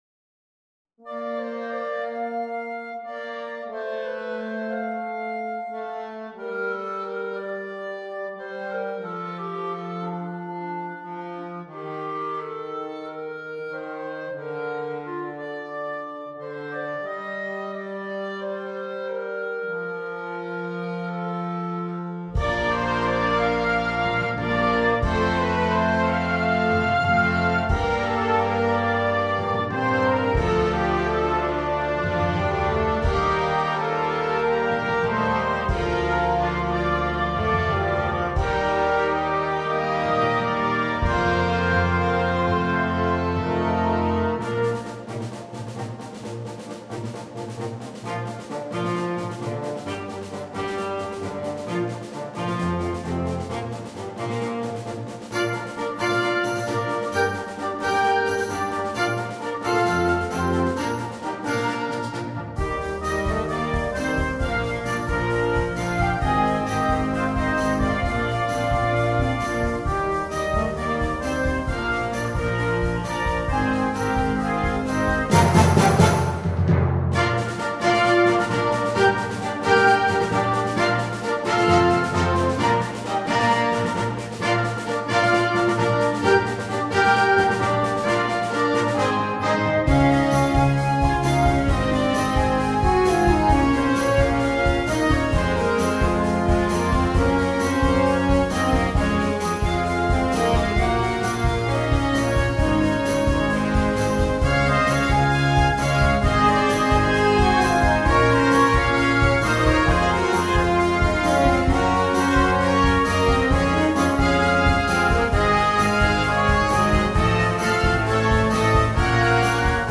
for band